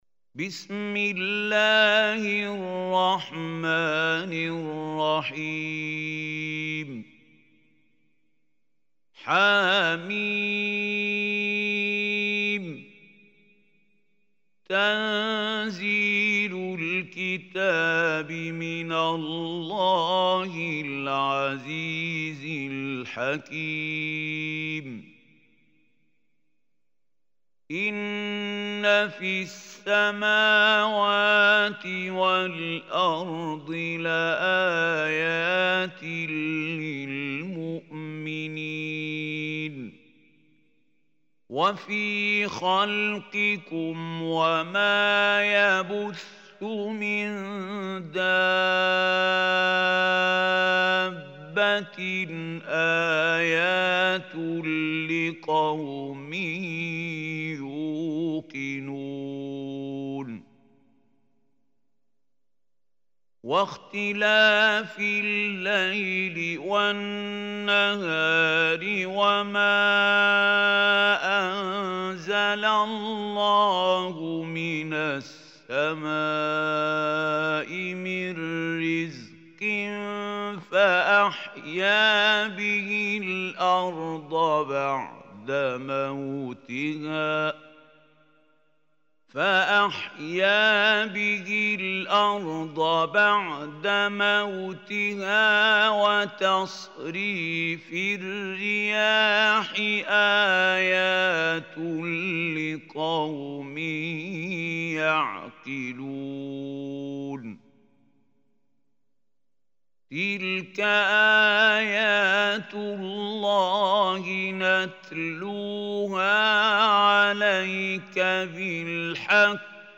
Surah Jathiyah Recitation Mahmoud Khalil Hussary
Surah Jathiyah is 45 surah of Holy Quran. Listen or play online mp3 tilawat / recitation in arabic in the beautiful voice of Sheikh Mahmoud Khalil Hussary.